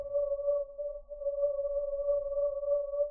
Percussion
alien2_f.wav